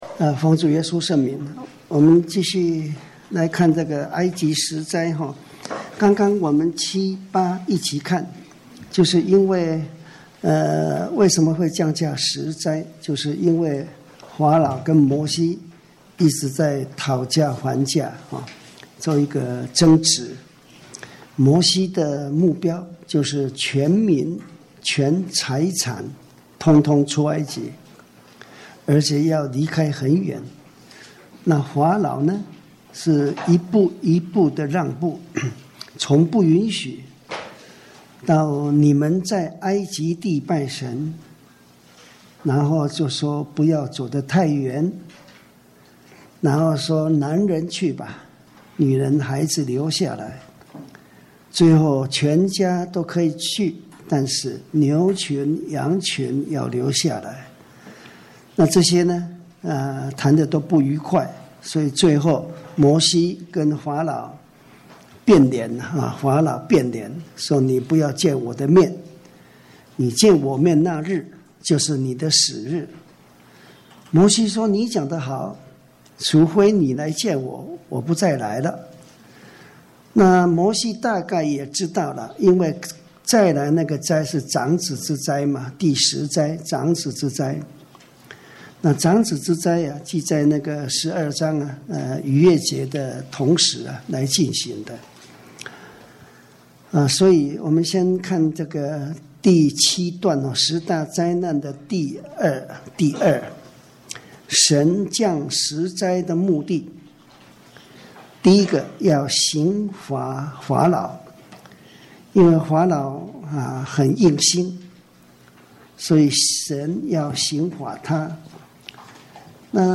講習會
地點 台灣總會 日期 02/17/2014 檔案下載 列印本頁 分享好友 意見反應 Series more » • 出埃及記 22-1 • 出埃及記 22-2 • 出埃及記 22-3 …